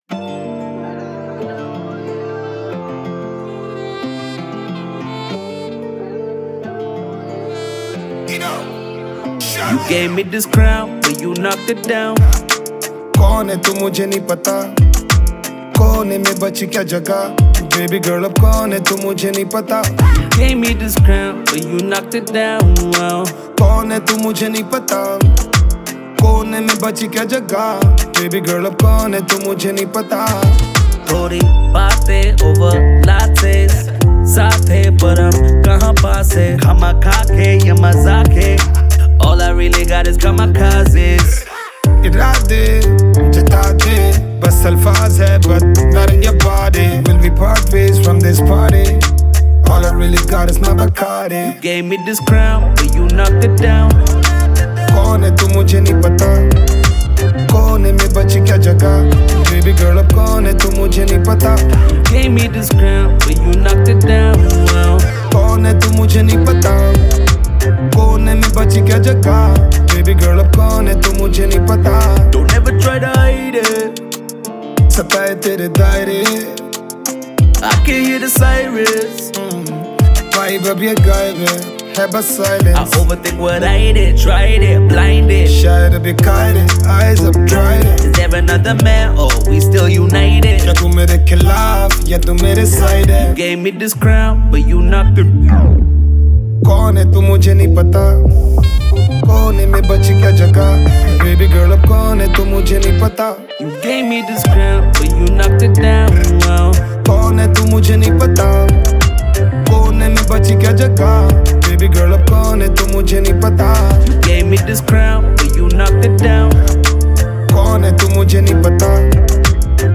2. INDIPOP MP3 Songs